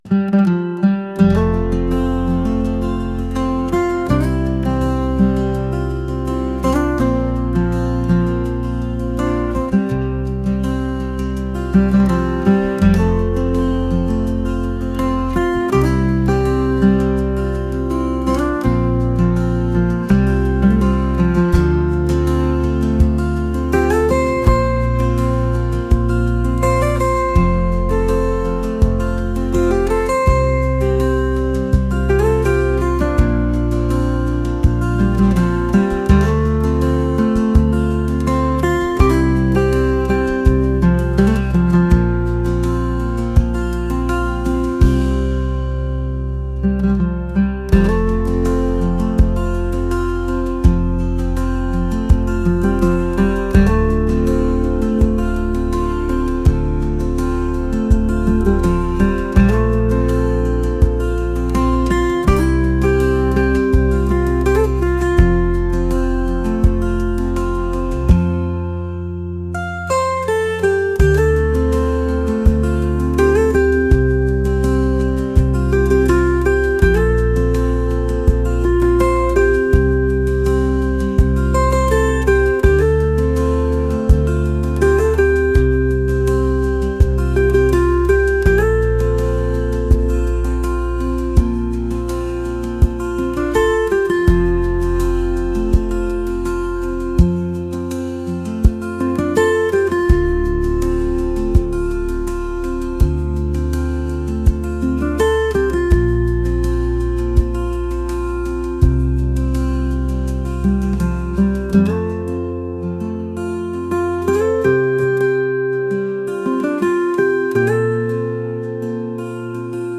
world | folk | acoustic